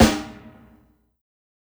SNARE_GUTER.wav